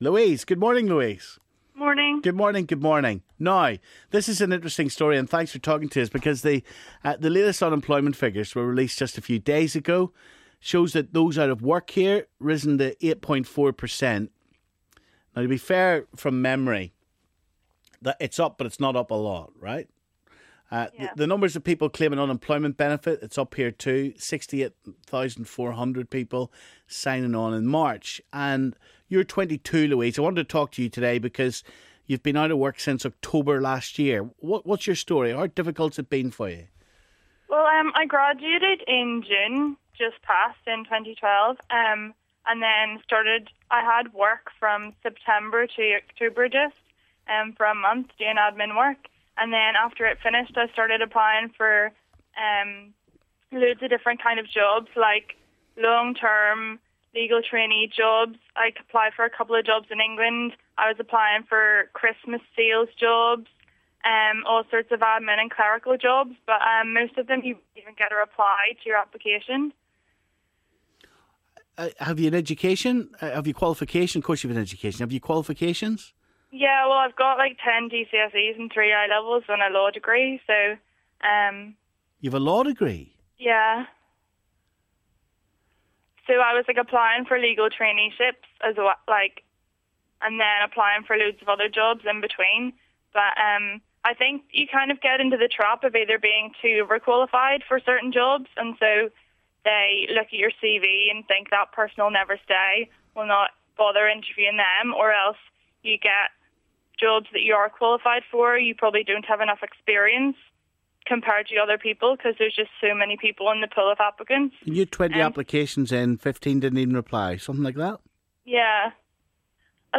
Unemployment figures have risen to 8.4%. Caller